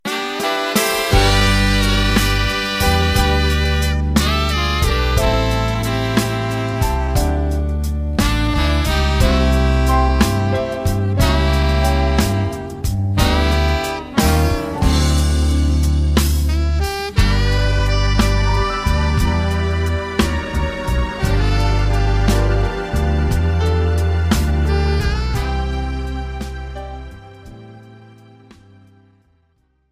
Without Backing Vocals. Professional Karaoke Backing Track.